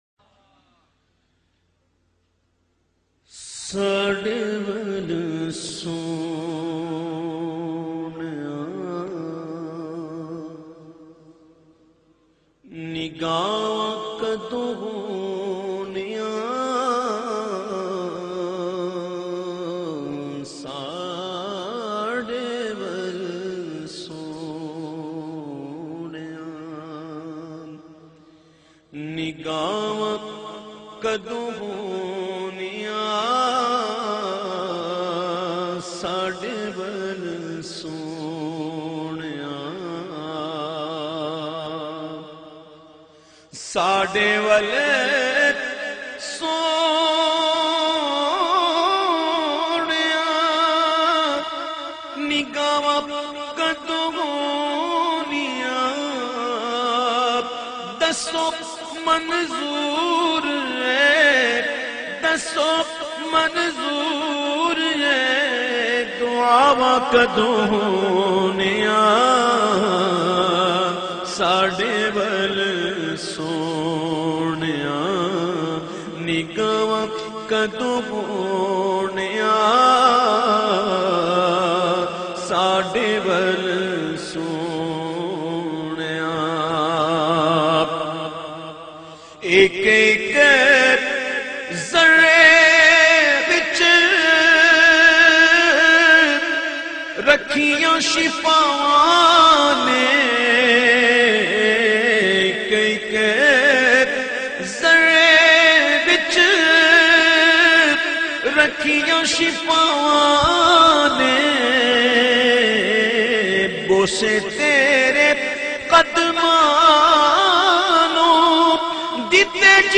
Punjabi Naat